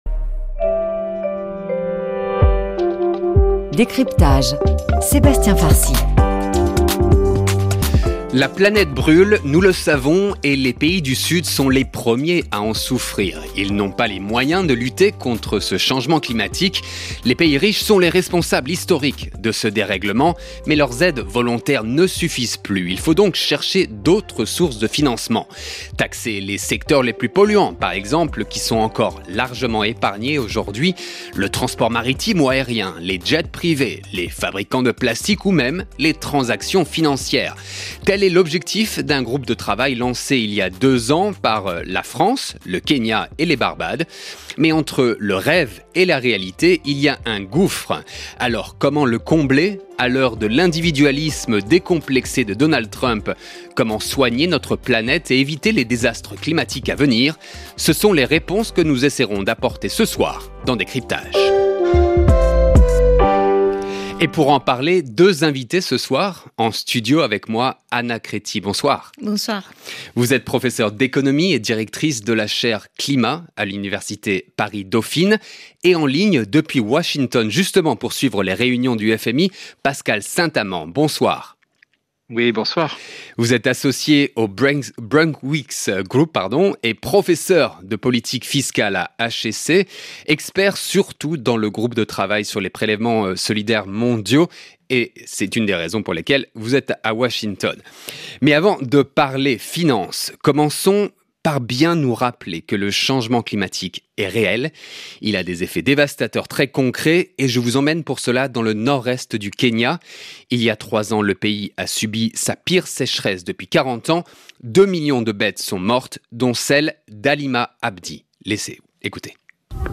Tranche d'information france 26/04 11h00 GMT - 26.04.2024